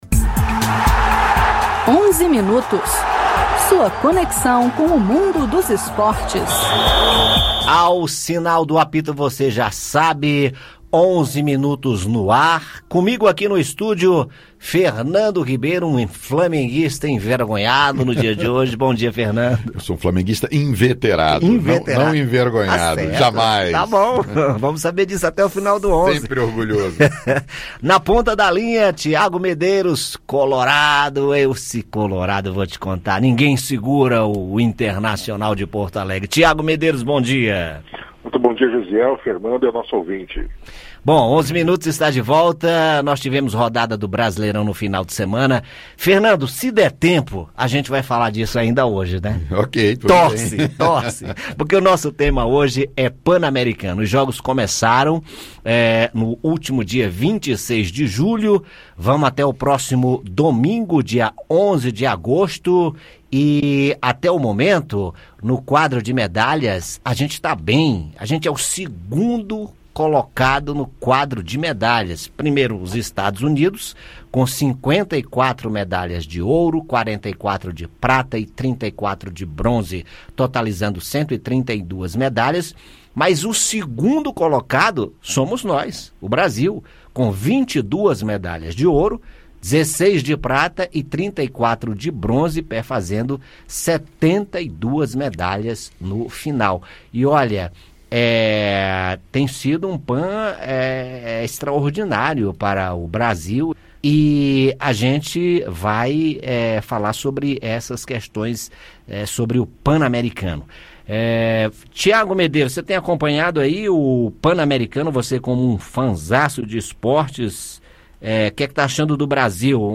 Os resultados do Brasil nos Jogos Pan-Americanos, o desastre do legado dos Jogos Olímpicos do Rio de Janeiro e a rodada do Brasileirão de futebol no fim de semana são os assuntos dos comentaristas da Rádio Senado no Onze Minutos desta segunda-feira (05).